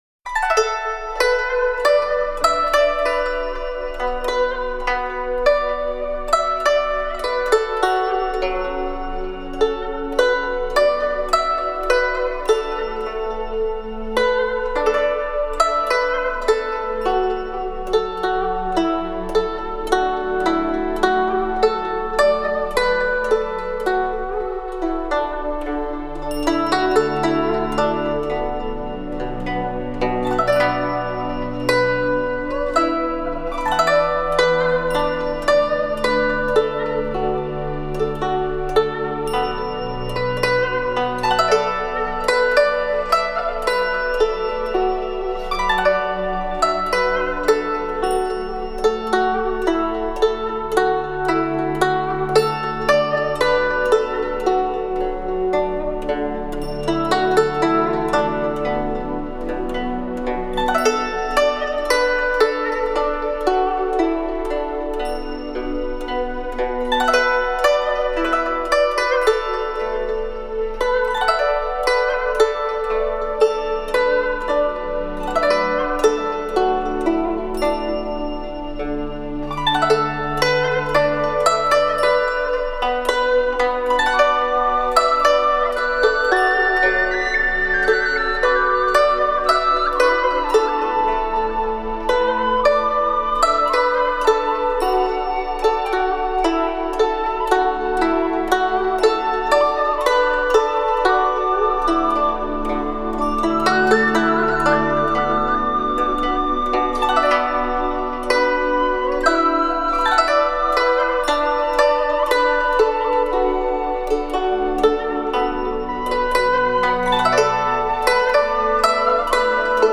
WOM_013_002_flower_elegy_z_yue.mp3